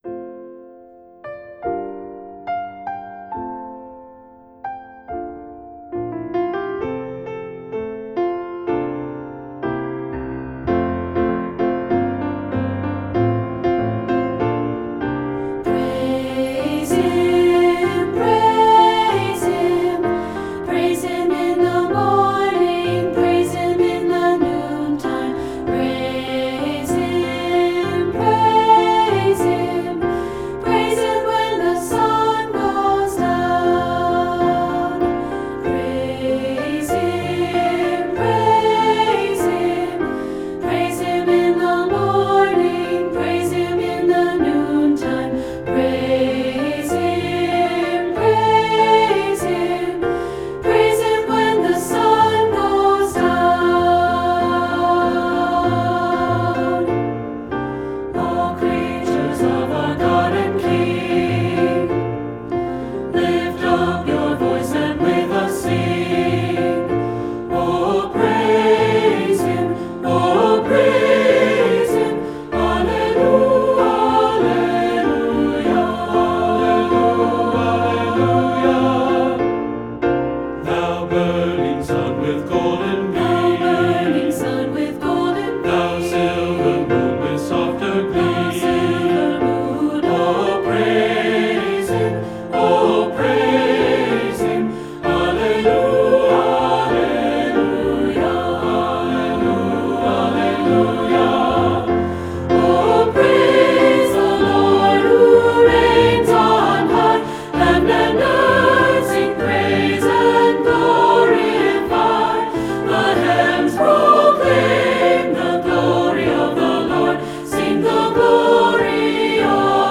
Voicing: SATB, Piano and Children's Choir